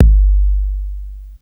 Kick_17.wav